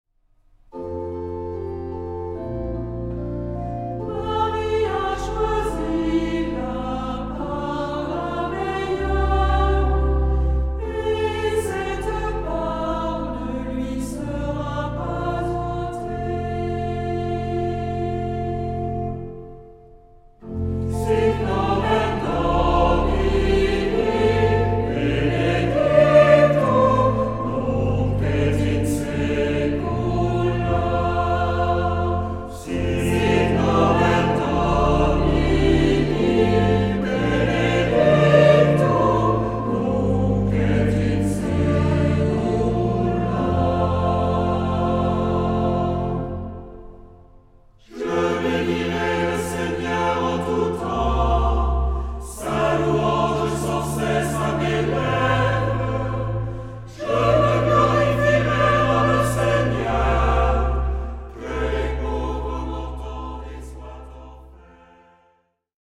SATB (4 voces Coro mixto) ; Partitura general.
tropario. Salmodia.